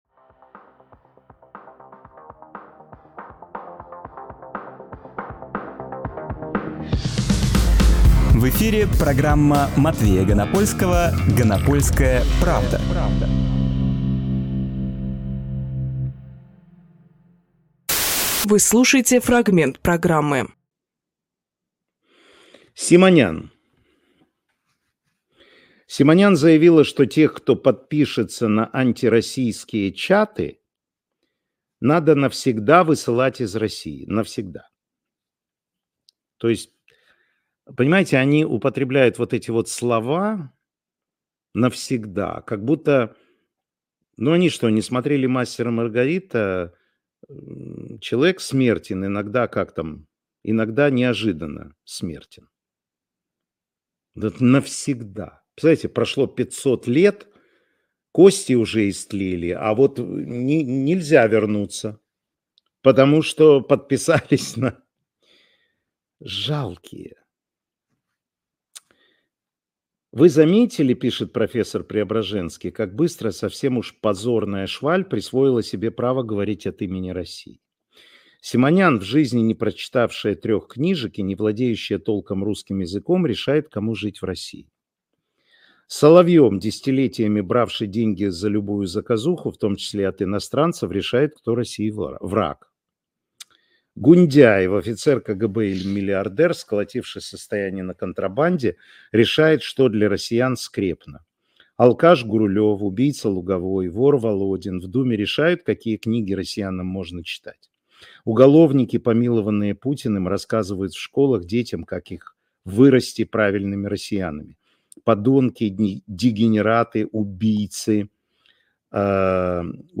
Матвей Ганапольскийжурналист
Фрагмент эфира от 30.03.24